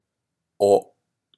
japanese_o_vowel.m4a